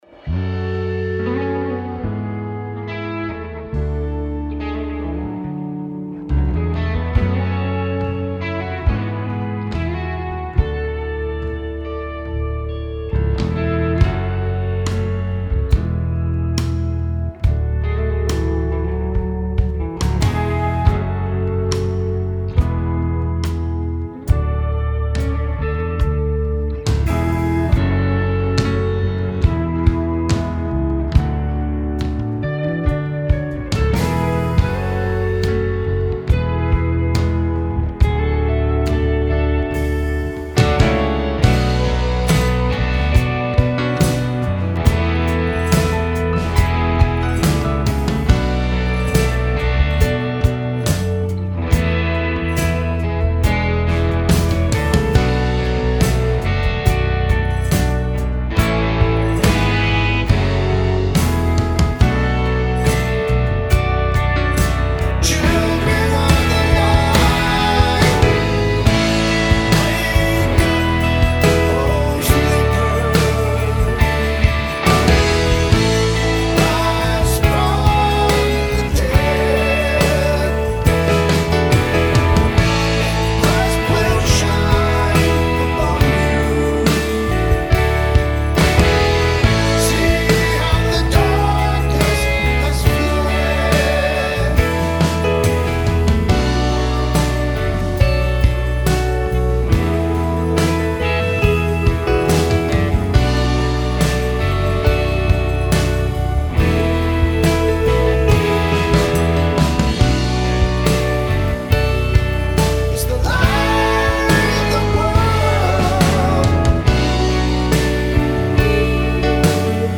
NoLeadVocal   Ephesians 5:14 Rock Resurrection Electric Guitars, Drums
Wake-Up-O-Sleeper-performance-track.mp3